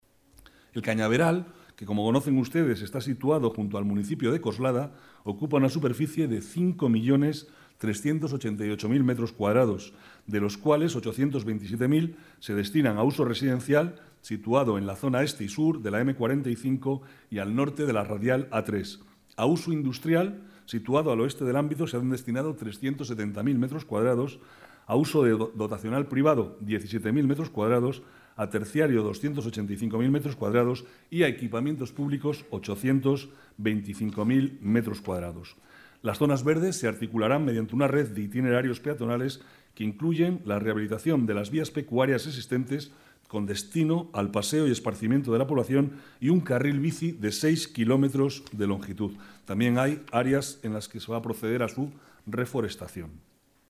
Nueva ventana:Declaraciones de Manuel Cobo, vicealcalde, sobre la vivienda protegida en el Cañaveral